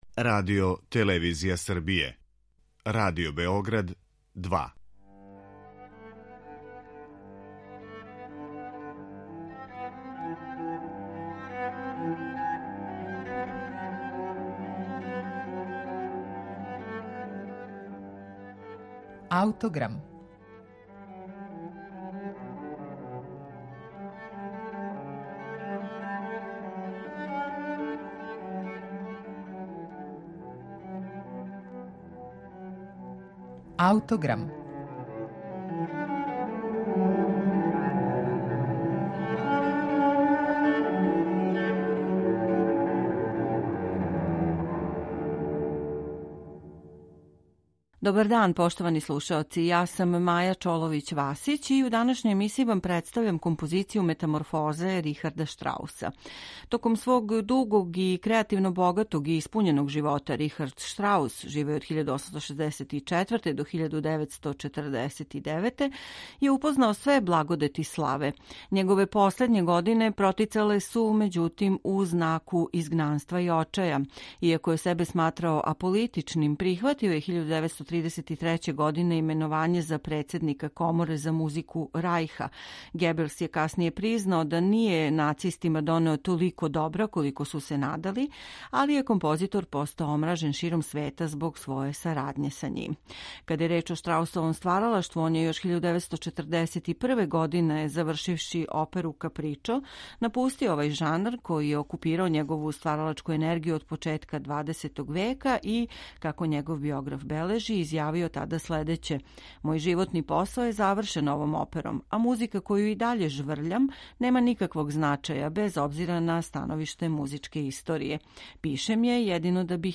студија за 23 гудача из 1945. године.